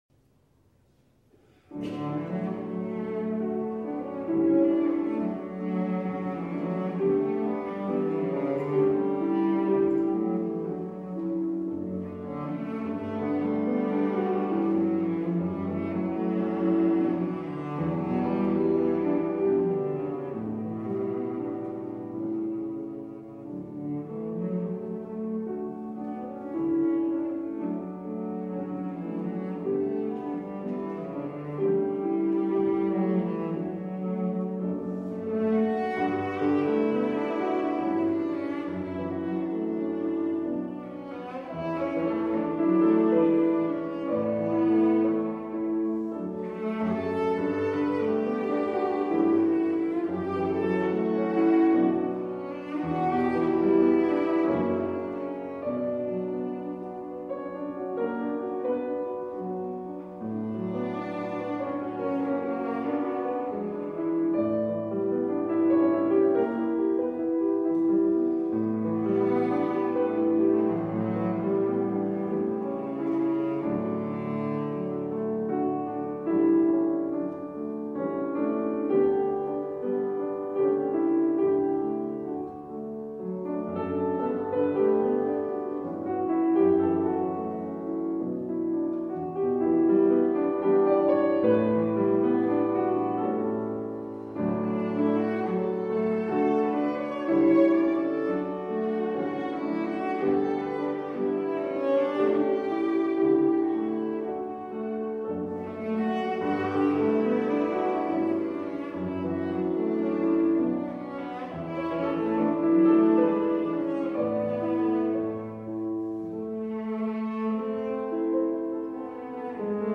Voicing: Cello Solo